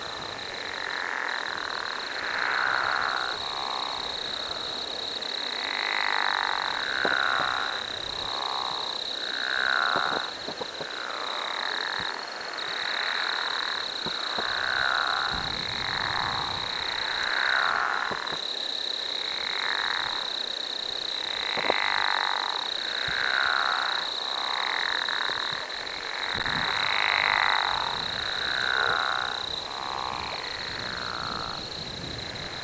The Pickerel Frog (Lithobates palustris), another less common species in Vermont, also usually begins calling in April, with a call that sounds like a brief snore.
Pickerel.wav